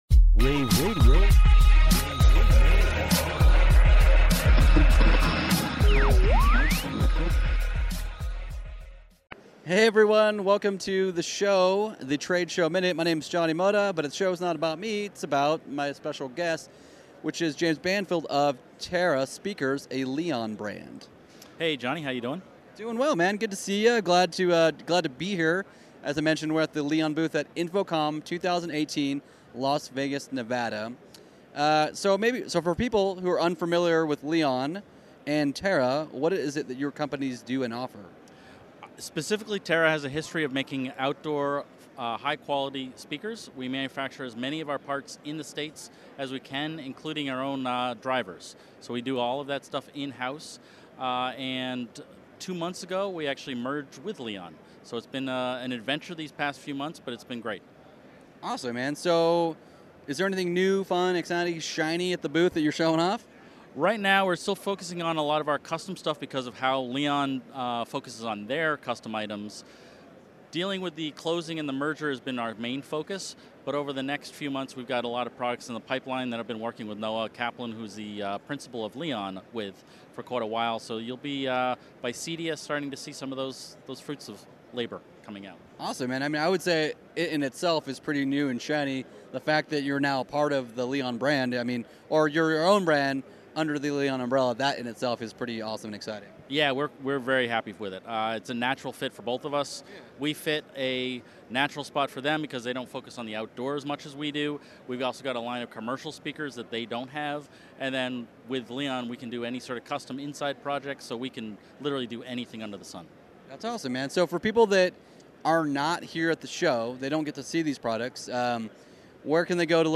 June 9, 2018 - InfoComm, InfoComm Radio, Radio, rAVe [PUBS], The Trade Show Minute,